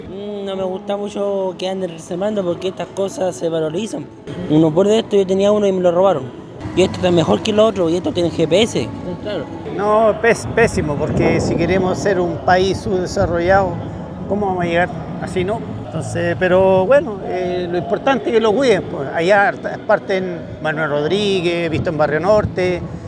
Los penquistas y usuarios de este servicio lamentaron que delincuentes estén vandalizando estos scooters que le sirven a las personas en trayectos cortos.